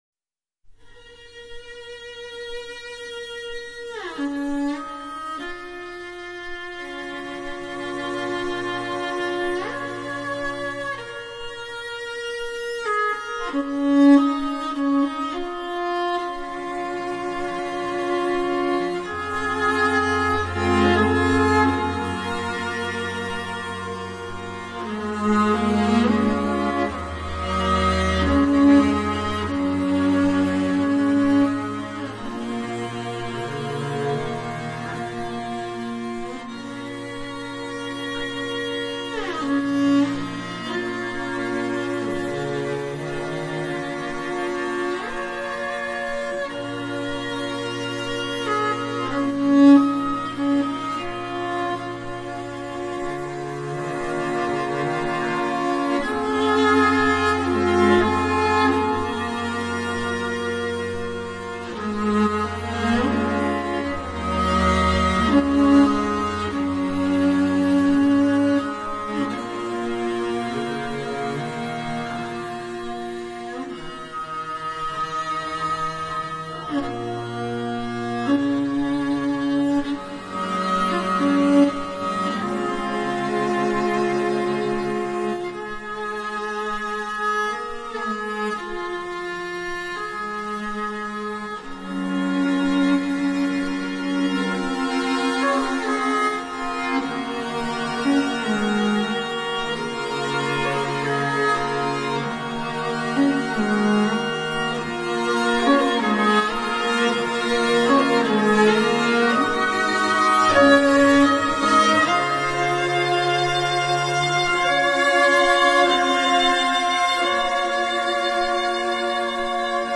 这一张独特蒙古原生态音乐CD，
包含了“呼麦”、蒙古长调和马头琴。
马头琴的演奏和浑然天成的蒙古长调配合完美；
凭个人通过口腔气流与牙齿发生共振或者通过喉部共振发生的声音
变化多样，或如乐器、或带磁性，稀有而奇特堪称蒙古族的魁宝。